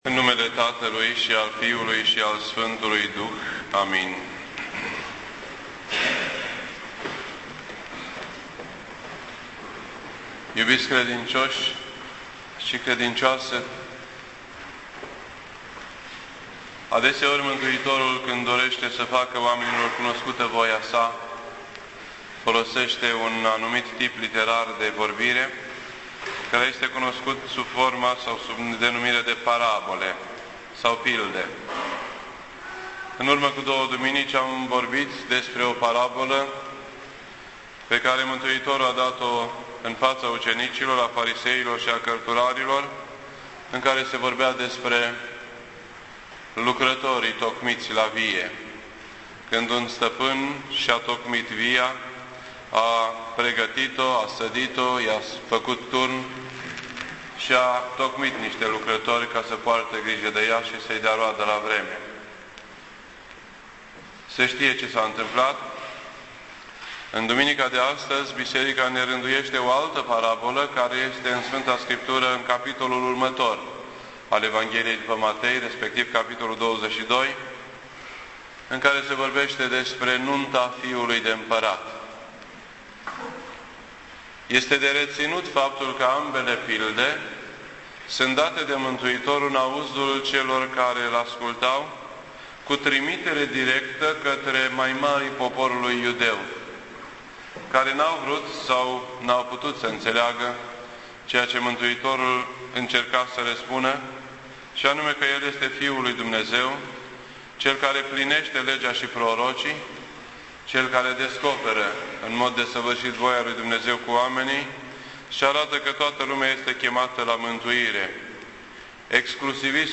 This entry was posted on Sunday, August 29th, 2010 at 9:10 PM and is filed under Predici ortodoxe in format audio.